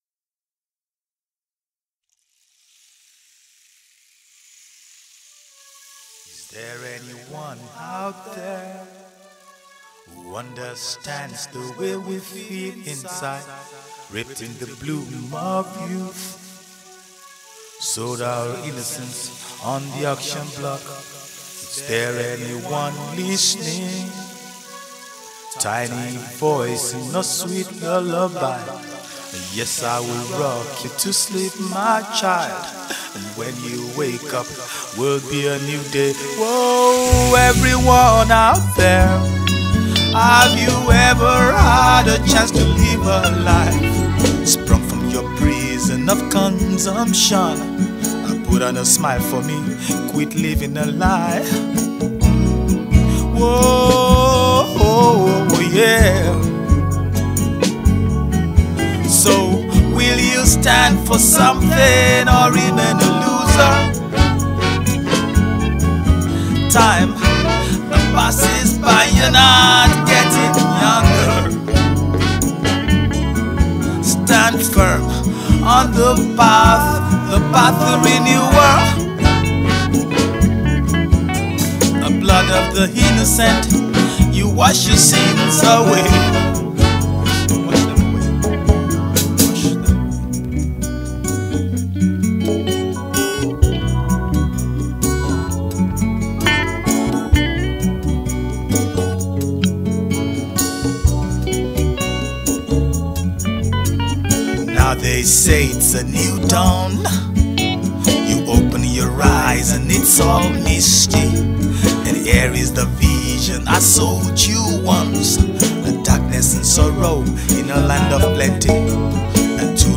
Yoruba Highlife
Nigerian Yoruba Fuji track